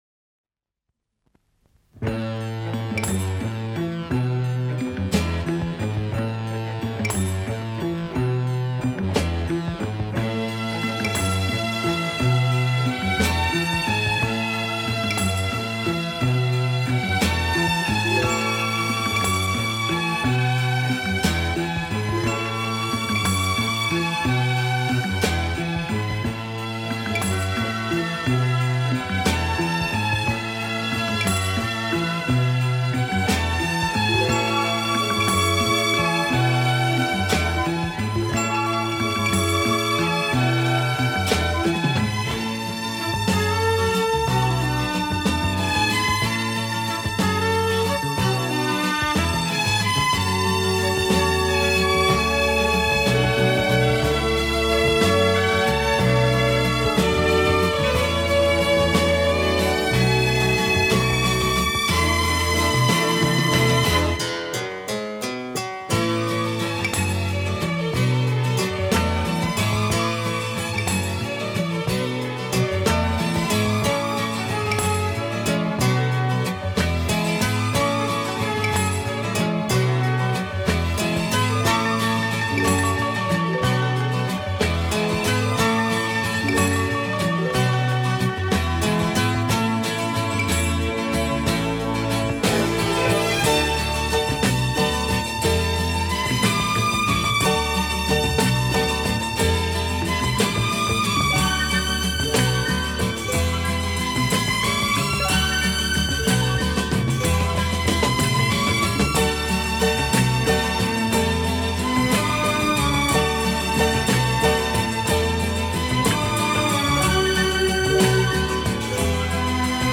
【顶级轻音乐】
其风格清新明朗，华丽纯朴，从不过分夸张。